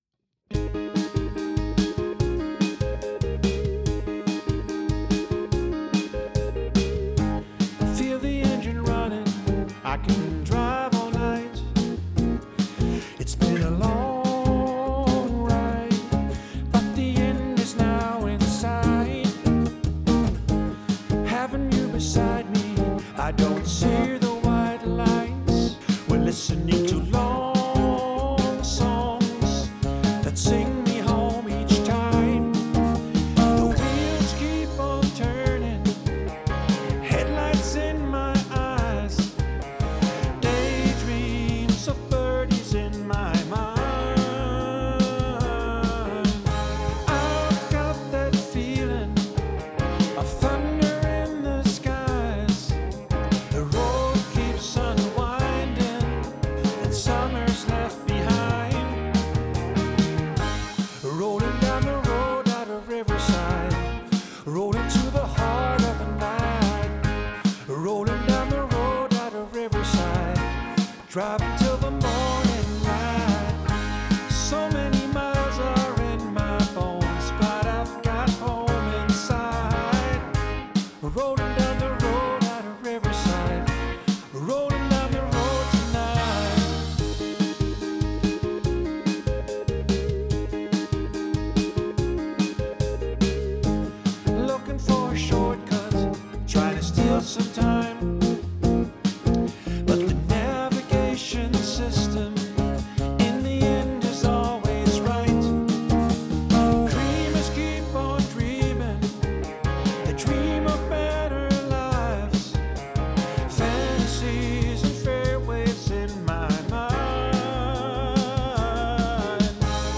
be your studio recording drummer for country rock folk
From a more traditional country style that might swing or shuffle to a modern rock country song that hits hard, I can give you what is needed to take your song to the next level.
Session Recording Drums Studio Drummer